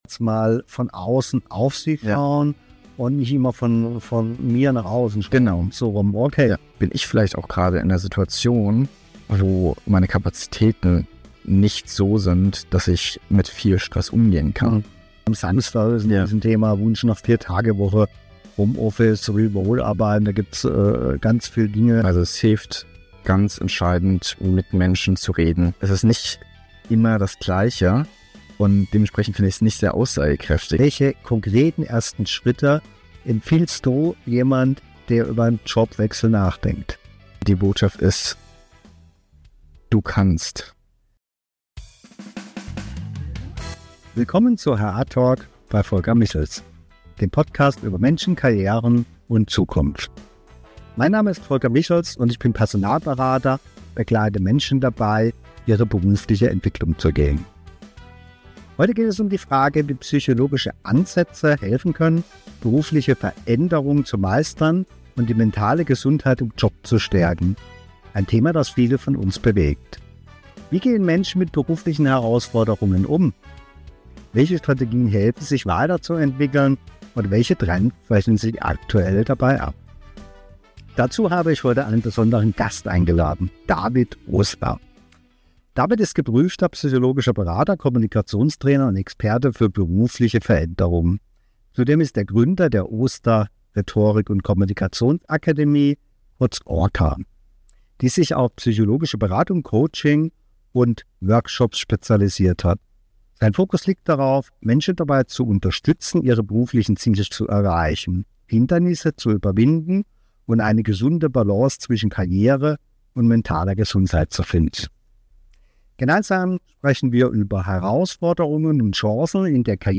Ein inspirierendes Gespräch für alle, die sich mit persönlicher Entwicklung, Karriereplanung und der Bedeutung von guter Führung auseinandersetzen möchten.